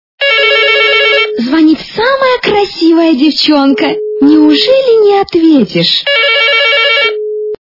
При прослушивании Звонит самая красивая девченка! - Неужели не ответишь? качество понижено и присутствуют гудки.